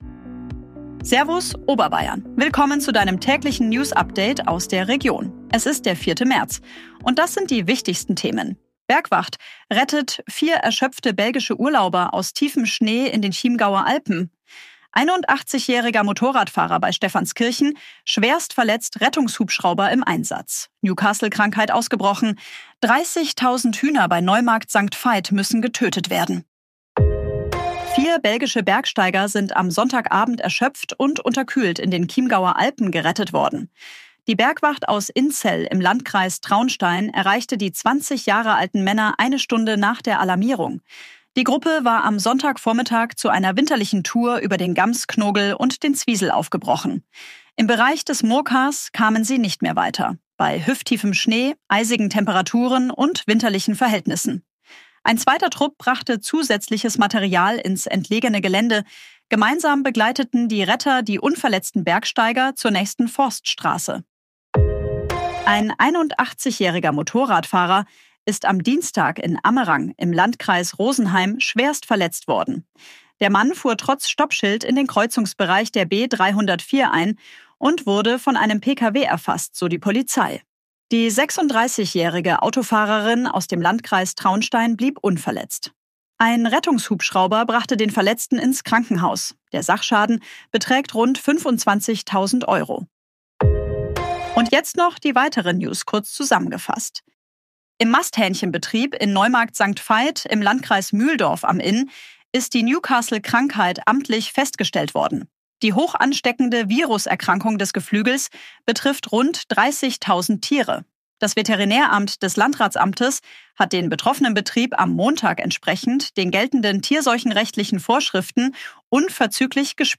Tägliche Nachrichten aus deiner Region